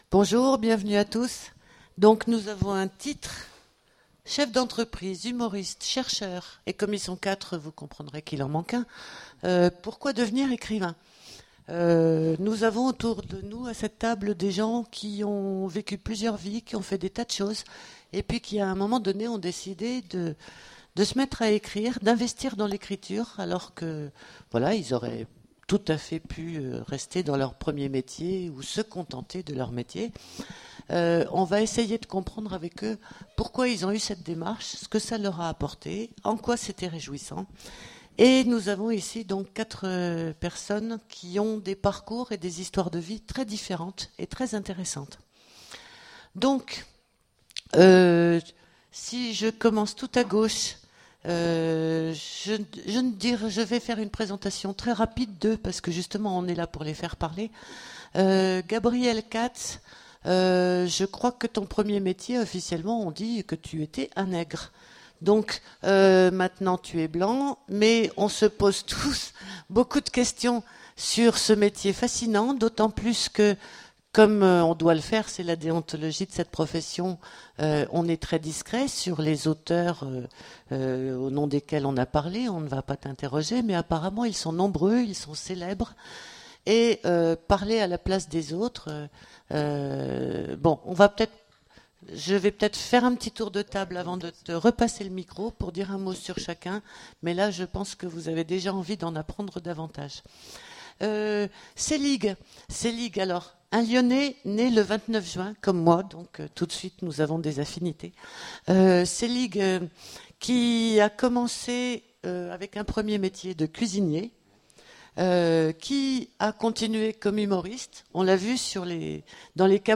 Imaginales 2015 : Conférence Chefs d'entreprise, humoriste, chercheur...